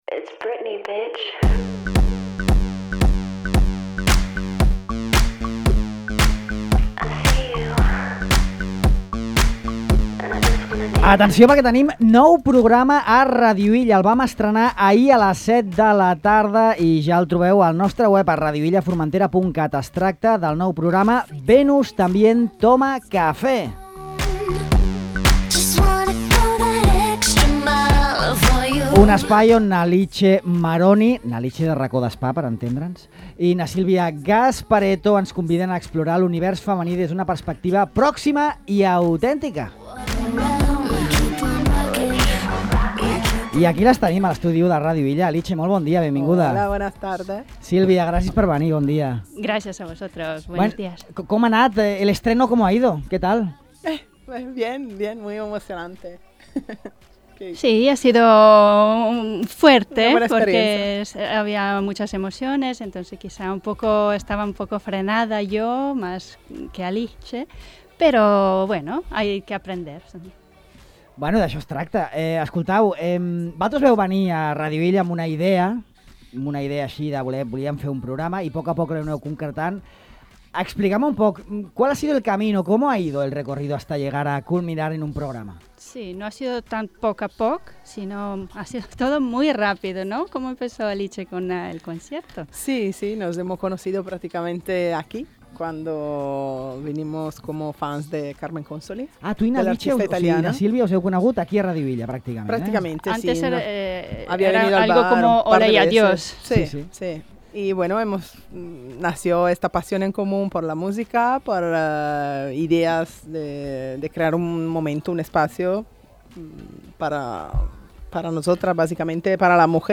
En aquesta entrevista exposen el procés creatiu que ha culminat en el llançament d’un programa que ens convida a explorar l’univers femení des d’una perspectiva propera i autèntica.